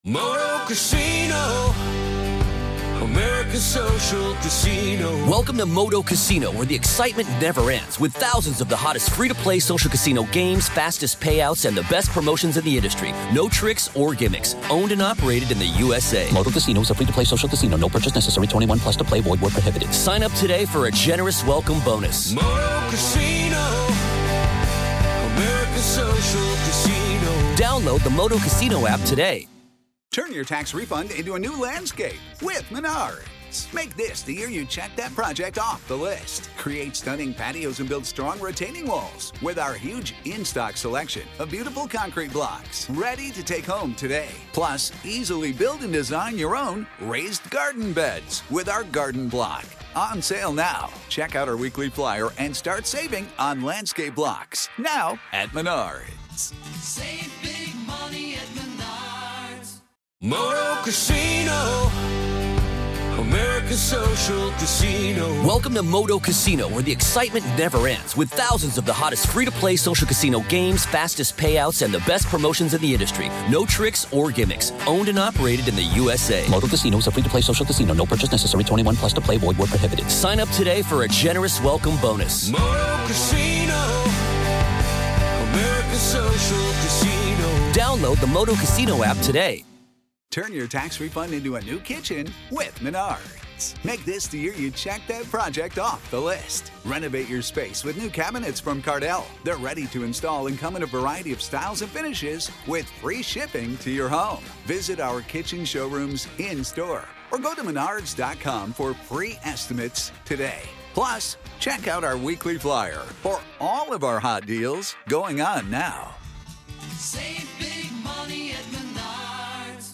This impactful motivational speeches compilation reminds you that progress beats perfection every time. Waiting to be perfect kills momentum-showing up consistently builds it.